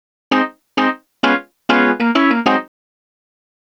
Swinging 60s 6 Organ-G#.wav